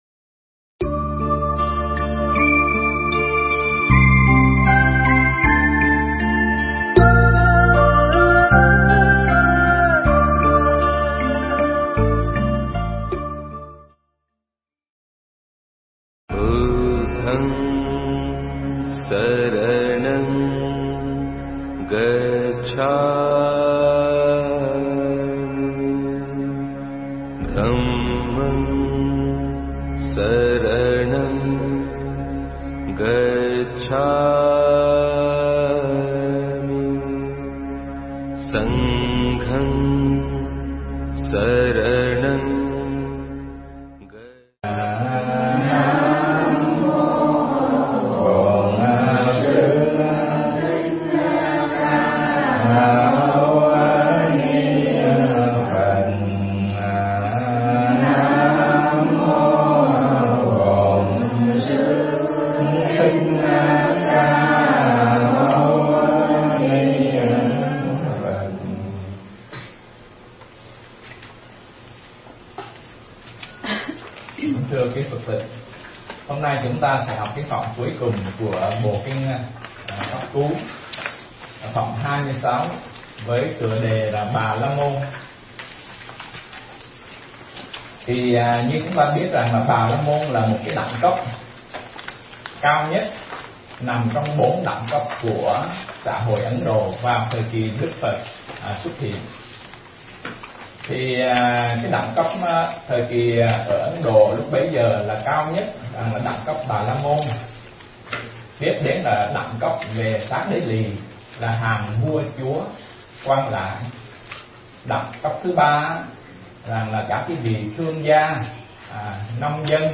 Mp3 Thuyết pháp Kinh Pháp Cú Phẩm Bà La Môn